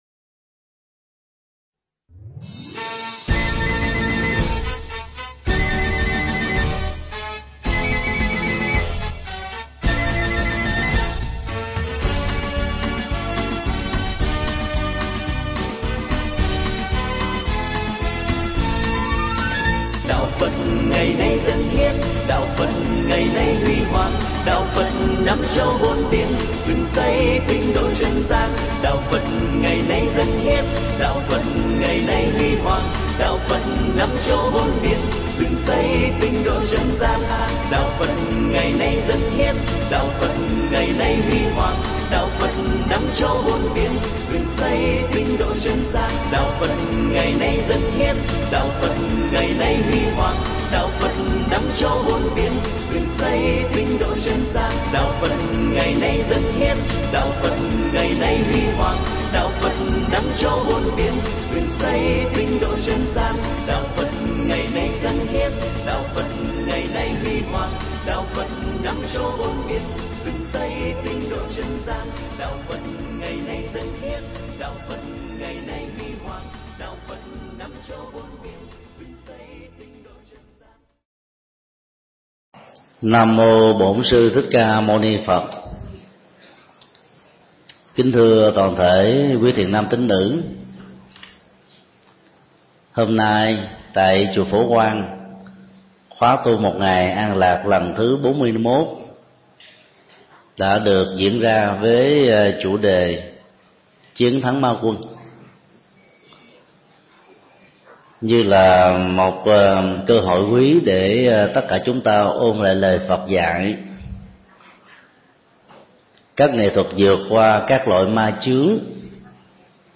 pháp thoại Chinh Phục Ma Quân
giảng tại Chùa Ấn Quang